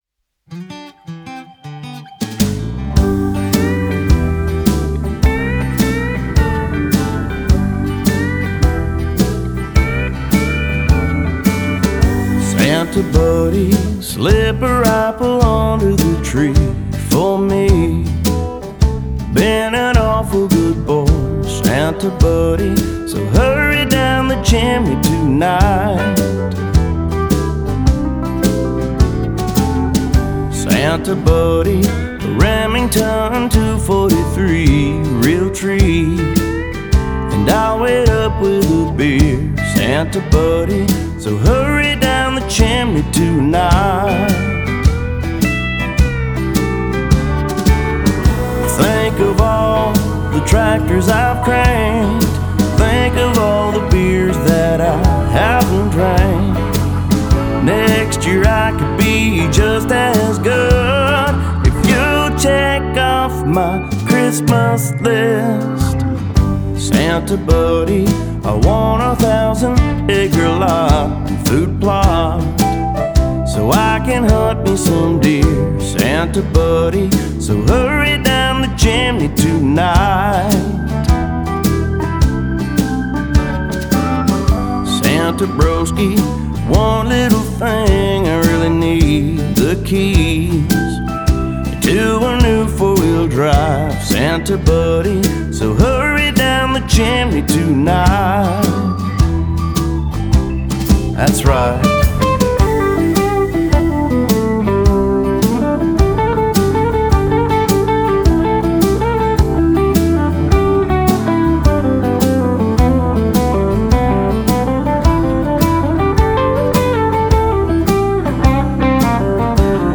Christmas single